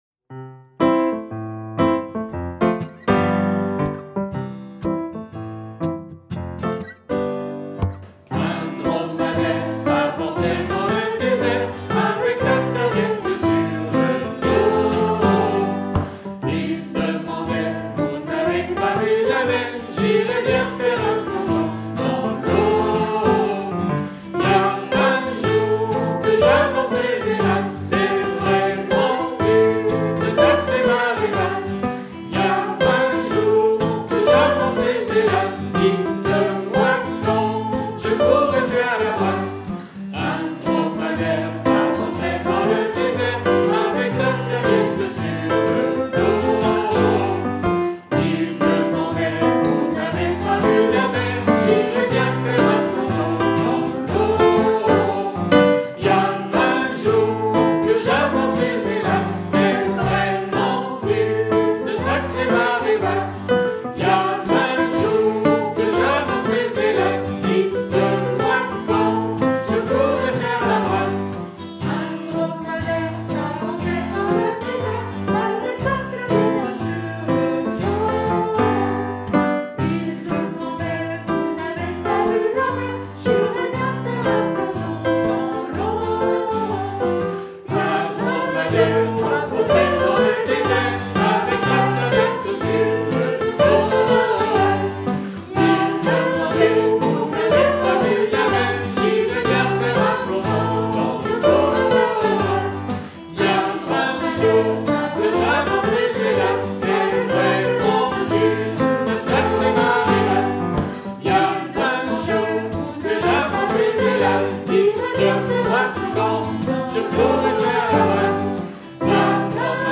à reprendre en canon au Cycle 3.
Elle est gaie et reste bien en tête.